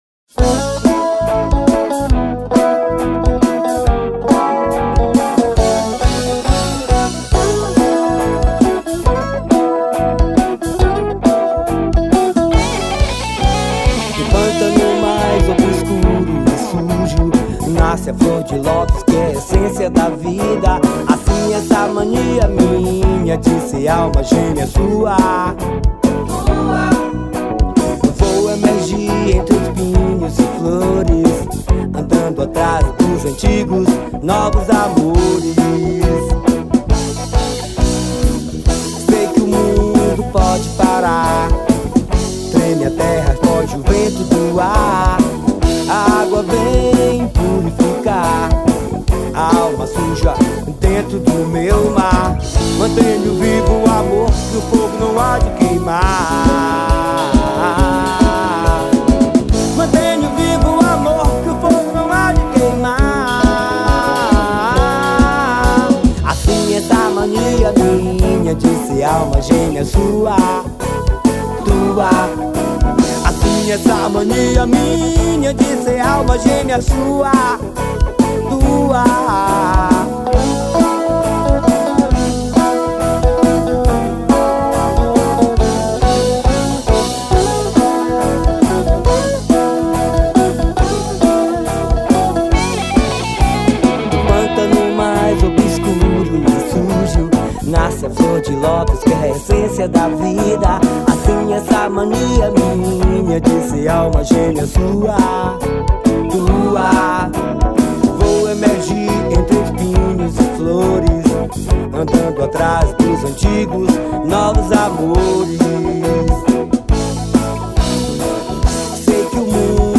2235   03:41:00   Faixa:     Forró